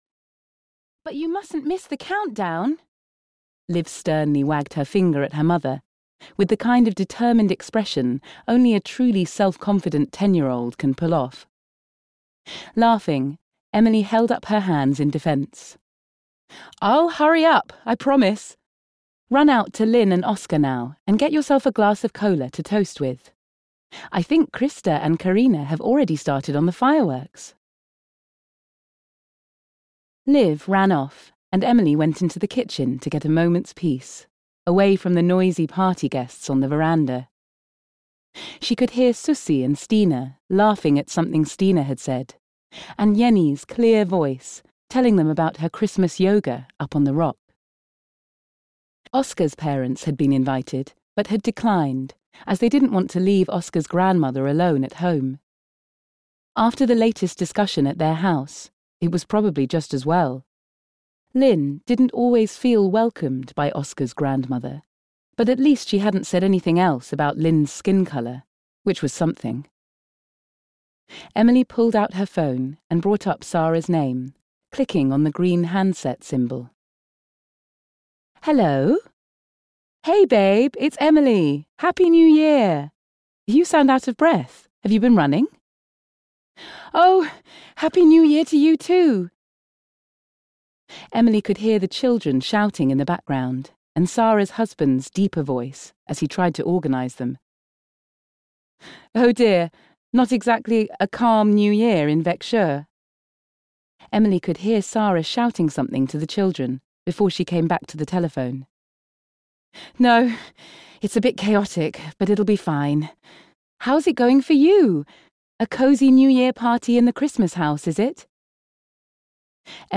Home for Easter (EN) audiokniha
Ukázka z knihy